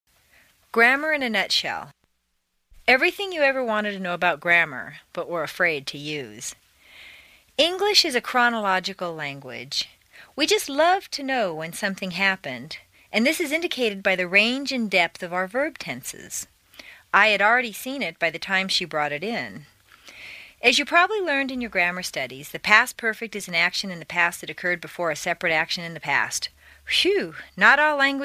在线英语听力室美式英语正音训练第51期:语法概述的听力文件下载,详细解析美式语音语调，讲解美式发音的阶梯性语调训练方法，全方位了解美式发音的技巧与方法，练就一口纯正的美式发音！